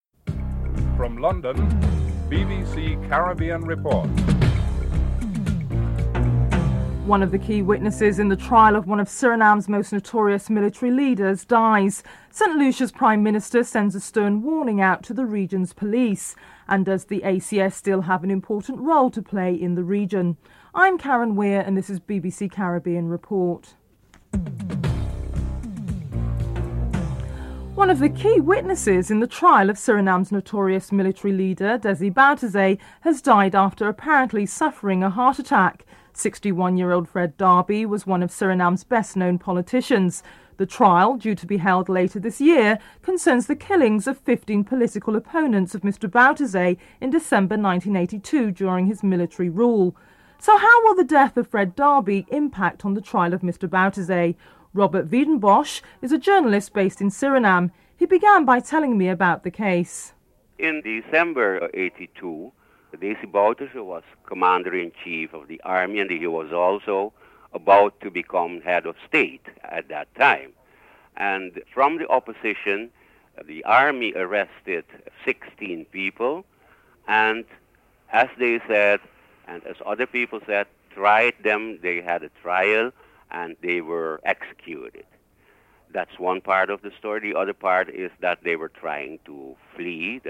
dc.formatStereo 192 bit rate MP3;44,100 Mega bits;16 biten_US